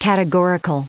added pronounciation and merriam webster audio
673_categorical.ogg